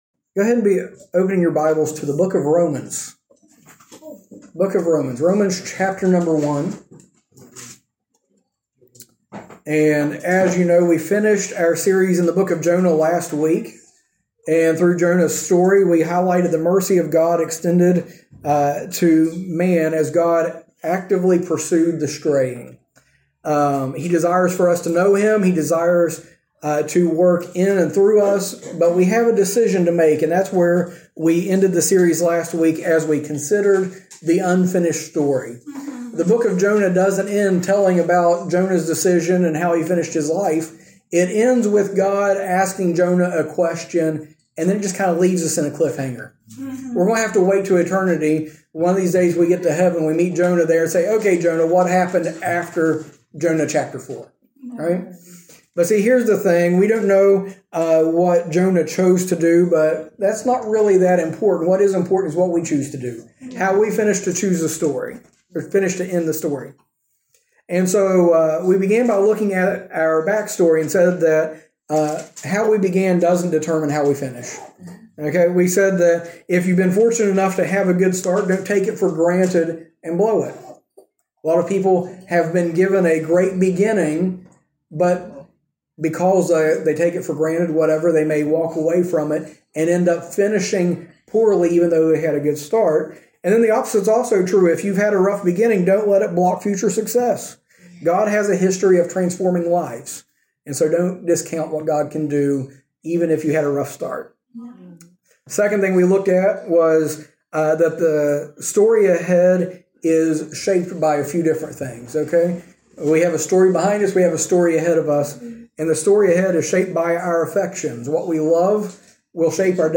In this sermon series, we go through Paul's letter to the Romans section by section as he shows how the Christian life should be.